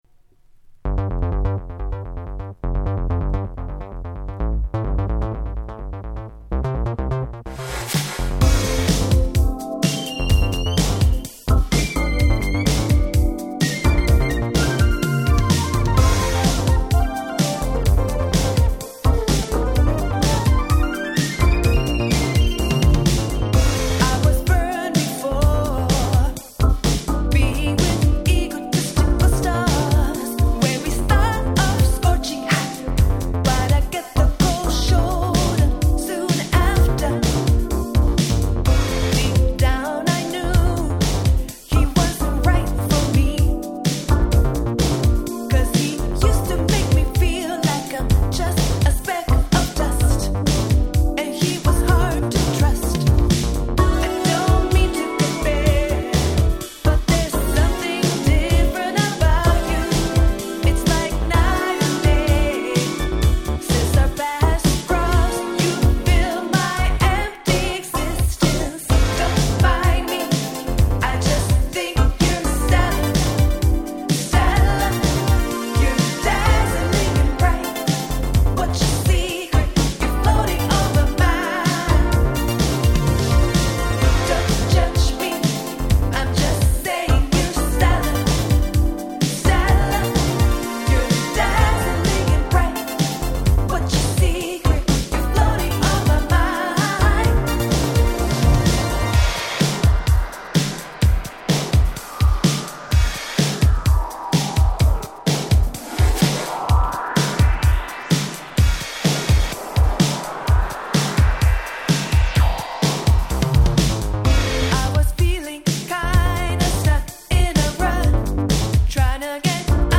国産Nice Disco Boogie/Modern Soul !!